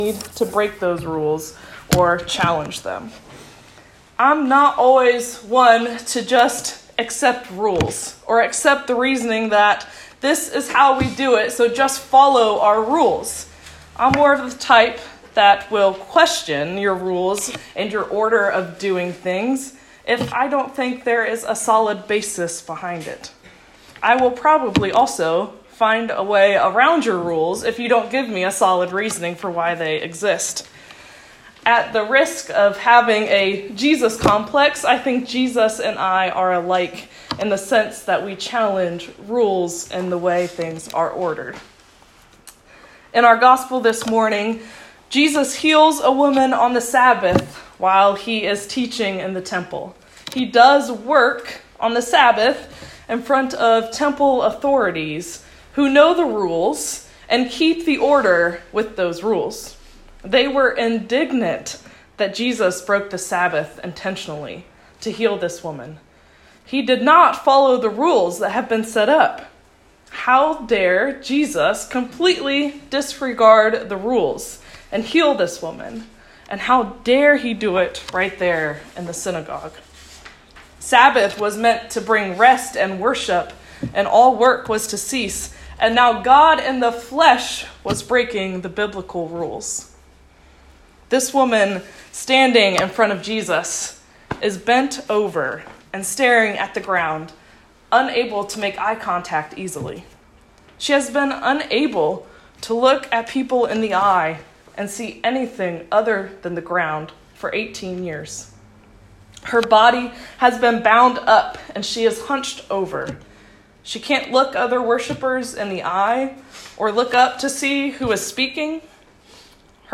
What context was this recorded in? The first part of the sermon did not get recorded!